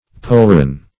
polron - definition of polron - synonyms, pronunciation, spelling from Free Dictionary
polron - definition of polron - synonyms, pronunciation, spelling from Free Dictionary Search Result for " polron" : The Collaborative International Dictionary of English v.0.48: Polron \Pol"ron\, n. See Pauldron .